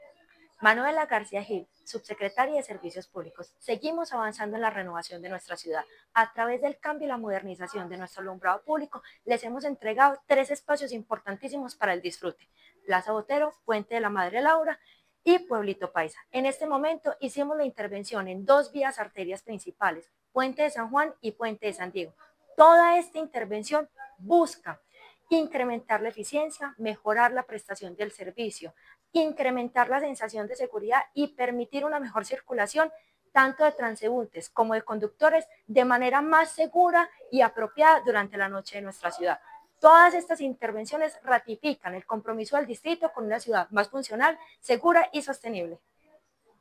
Declaraciones subsecretaria de Servicios Públicos, Manuela García Gil La Alcaldía de Medellín modernizó el sistema de alumbrado público en los puentes de San Juan y el de San Diego.
Declaraciones-subsecretaria-de-Servicios-Publicos-Manuela-Garcia-Gil.mp3